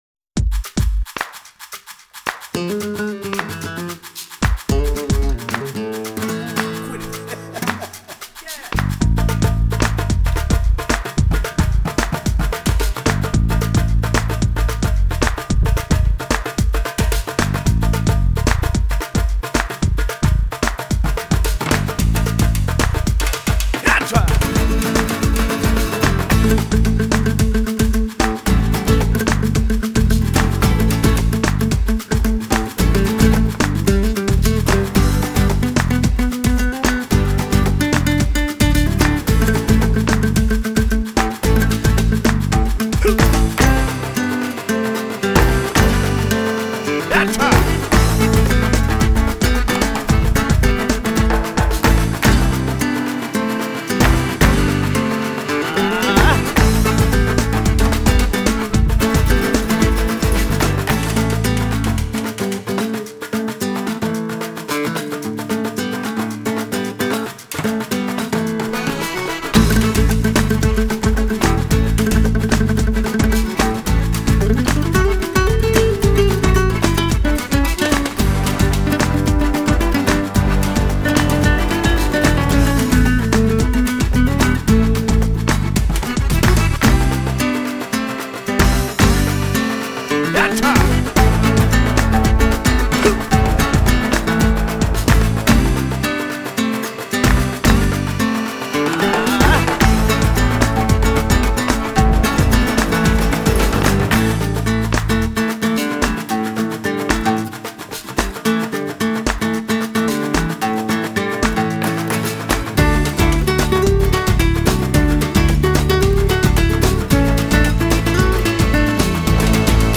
类型:Flamenco